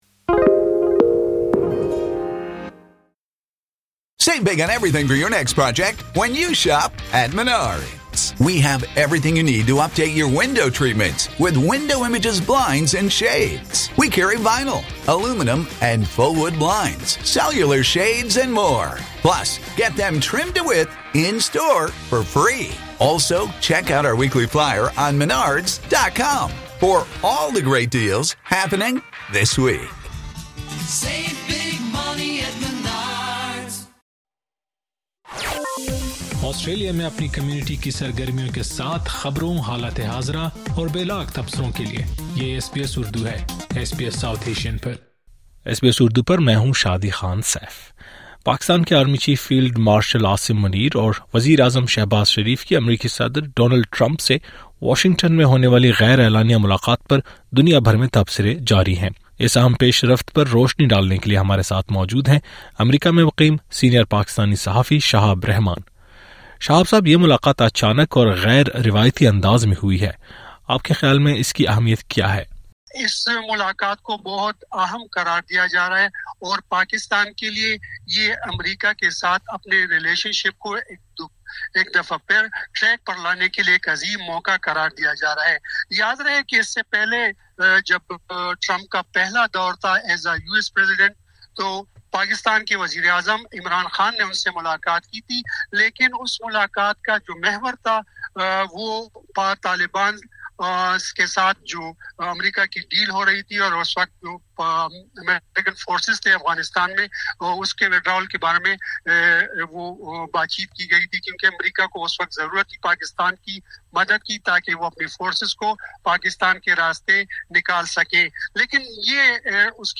پاکستان کے آرمی چیف فیلڈ مارشل عاصم منیر اور وزیراعظم شہباز شریف کی امریکی صدر ڈونلڈ ٹرمپ سے ہونے والی غیر اعلانیہ ملاقات پر دنیا بھر میں تبصرے جاری ہیں۔ اس اہم پیش رفت پر روشنی ڈالنے کے لیے ہمارے ساتھ موجود ہیں امریکہ میں مقیم سینئر پاکستانی صحافی